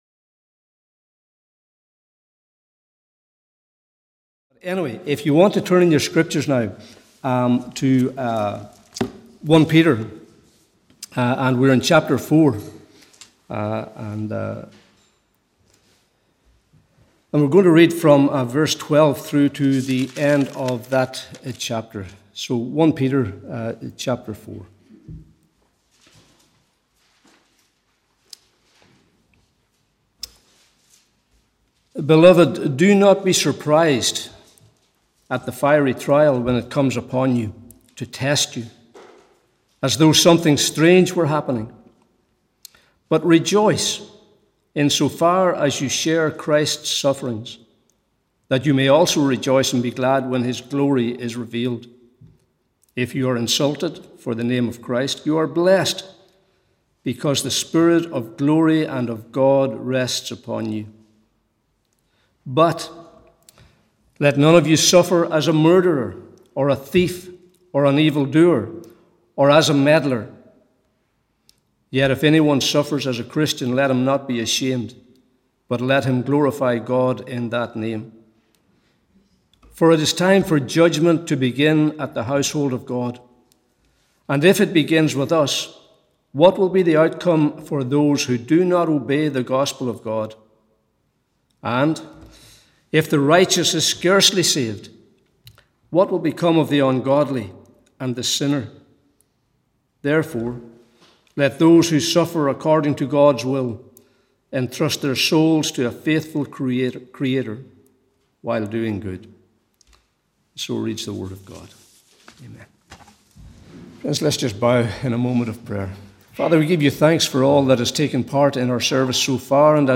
Morning Service 5th June 2022